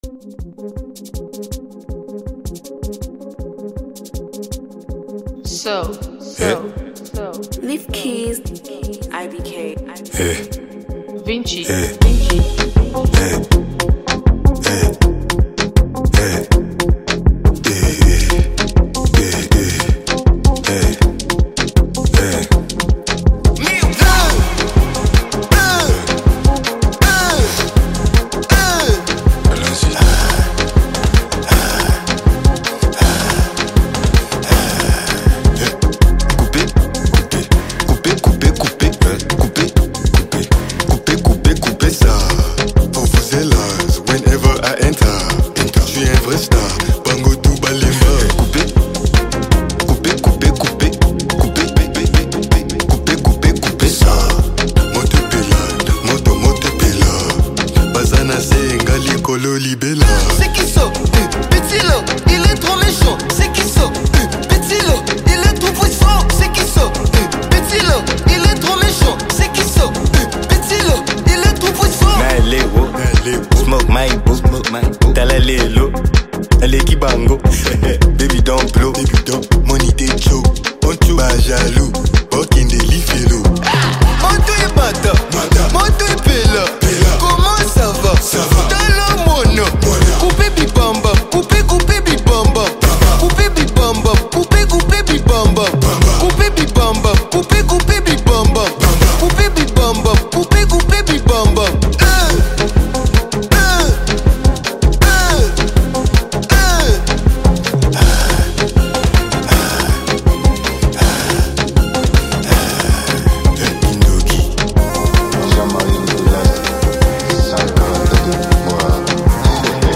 smooth vocals